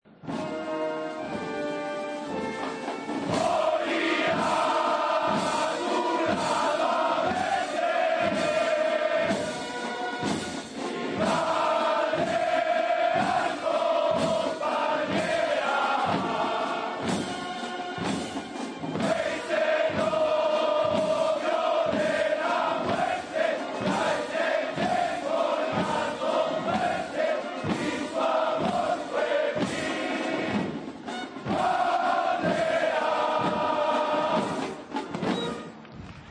Así ha sonado el desembarco de la Legión con el traslado del Cristo de Mena